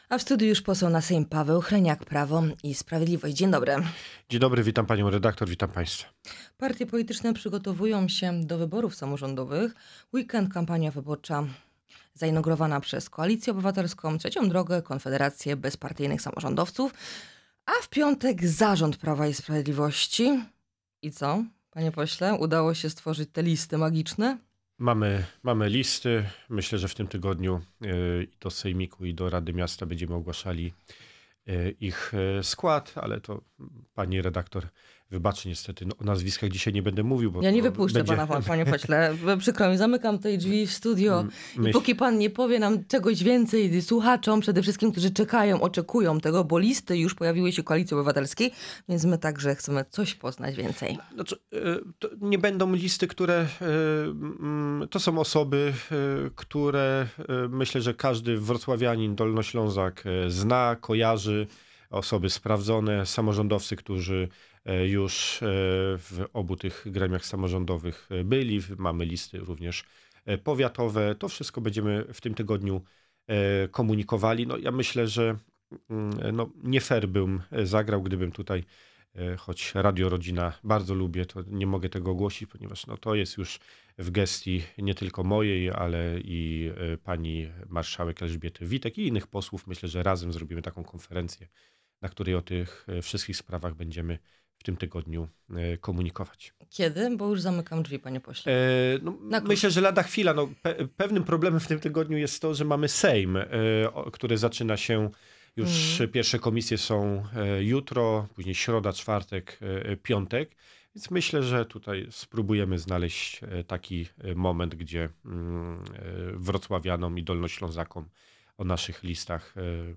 Partie polityczne przygotowują się do wyborów samorządowych i w weekend zainaugurowały kampanię wyborczą. O wyborach samorządowych, kondycji Prawa i Sprawiedliwości przed nadchodzącymi wyborami, komisji ds. Pegasusa oraz polityce obronnej naszego kraju rozmawiamy z posłem PiS – Pawłem Hreniakiem.